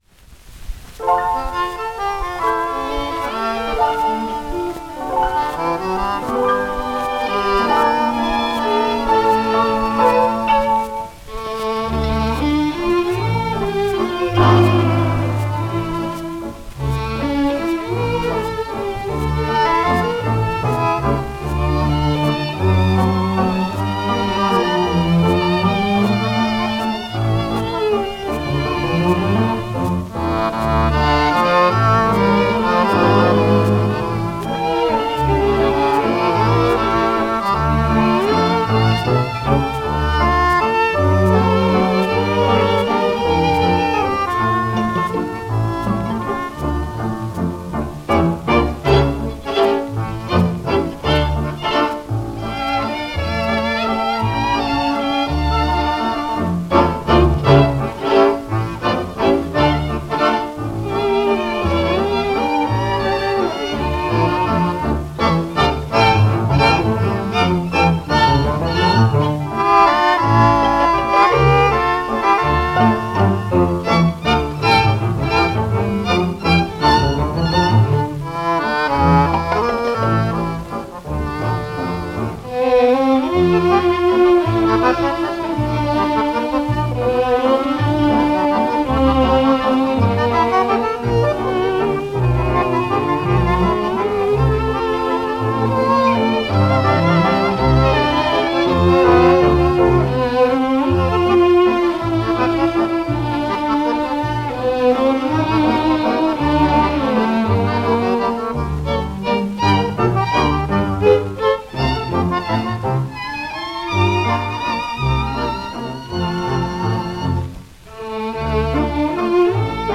Vals
HIS RUSSIAN GYPSY ENSEMBLE
Жанр: Вальс